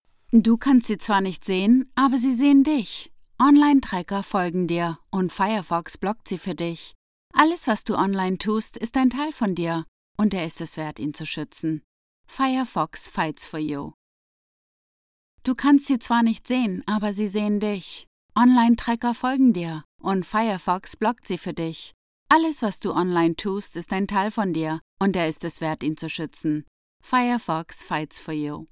Female
She records from her own professional studio and offers fast turnaround Her voice is international, smooth, confident, and professional, with a warm, authentic, and engaging millennial tone. A sophisticated trans-Atlantic sound ideal for global brands and storytelling.
Radio Commercials
De Conversational Millennial